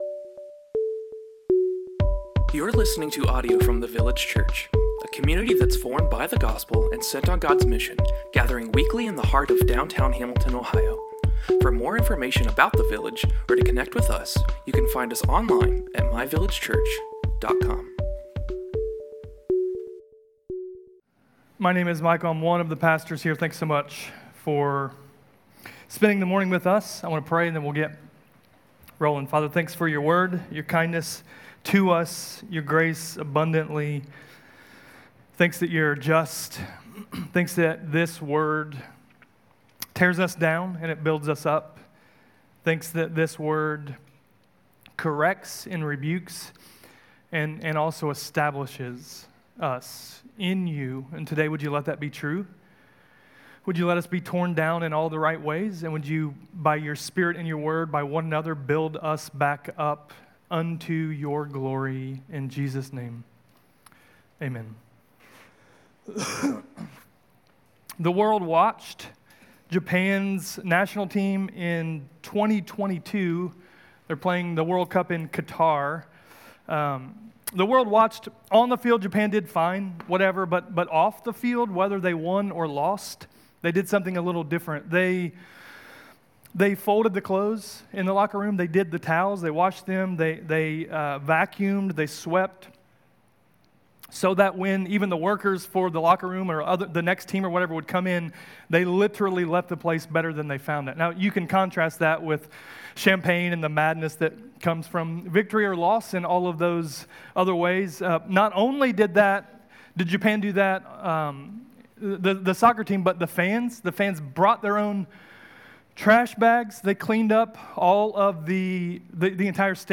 A sermon in our series, Romans: Not Ashamed.